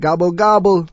l_gobblegobble.wav